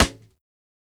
SNARE_DEATH_DO_US_PART_2.wav